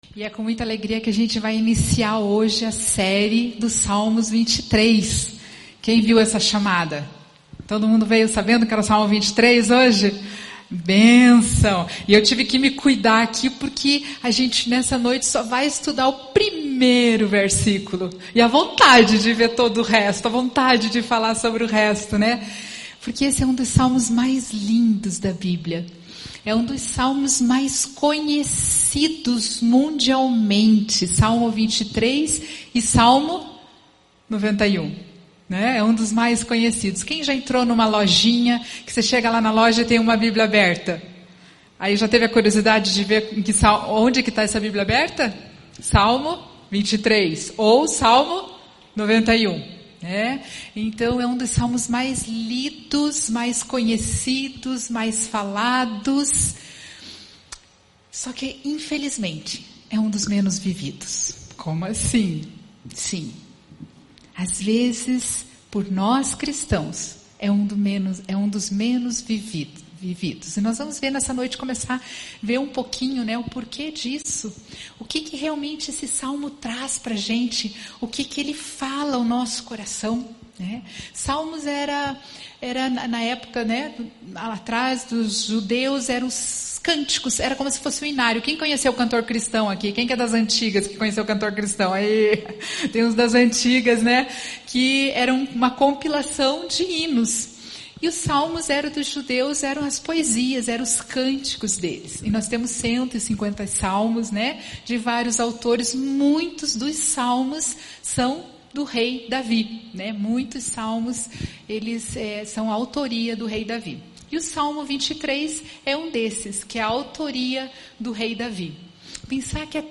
Mensagem
na Igreja Batista do Bacacheri.